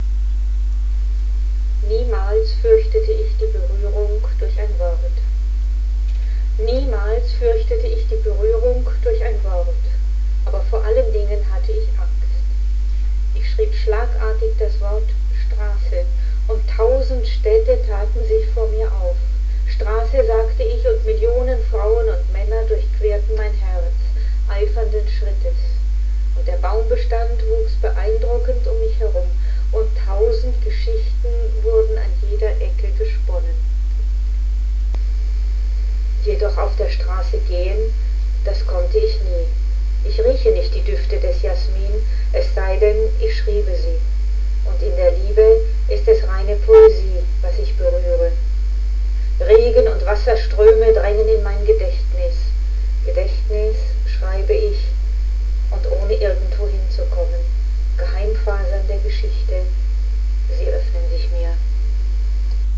rezitiert Menassa